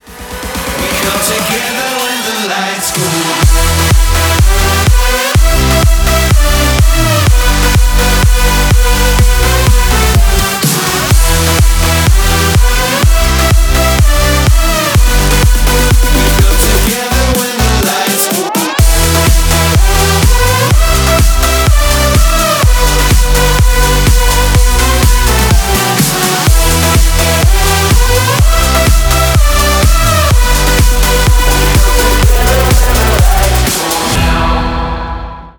Танцевальные
громкие # клубные